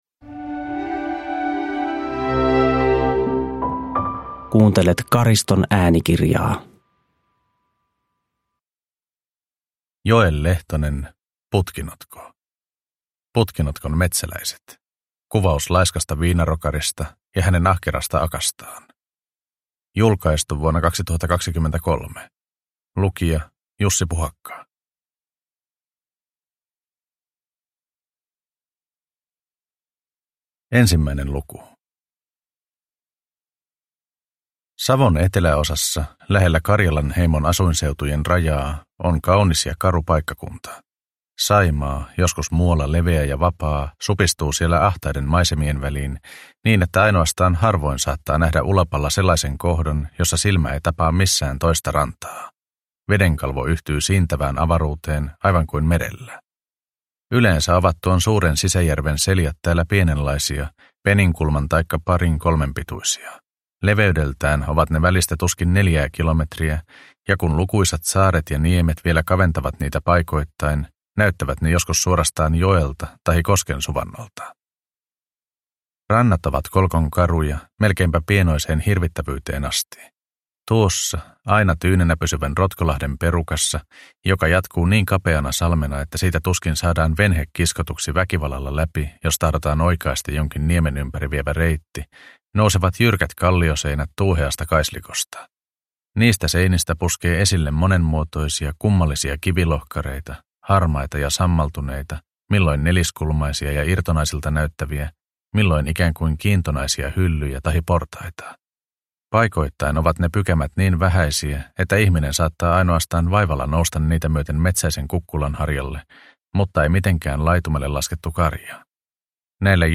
Putkinotko – Ljudbok – Laddas ner